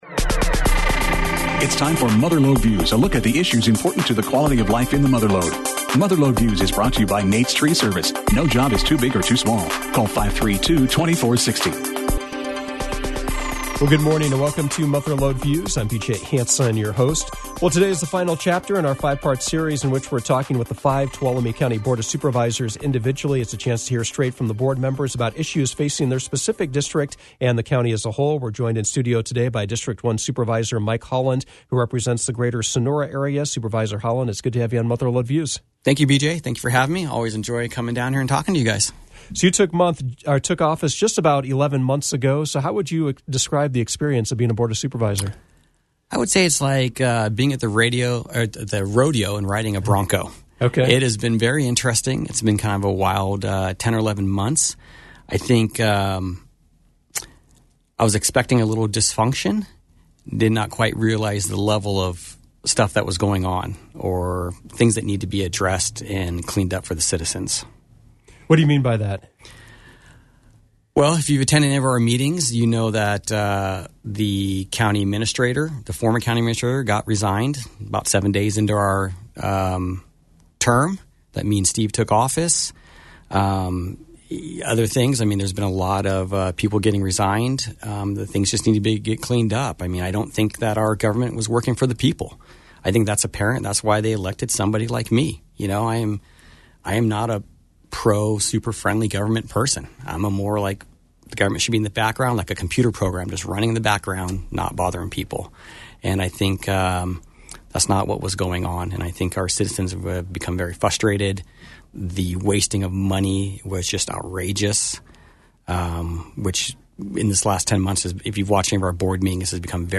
Mother Lode Views featured District One Tuolumne County Supervisor Mike Holland. It is the final show in our series featuring all five members speaking about issues facing their specific district and the county as a whole.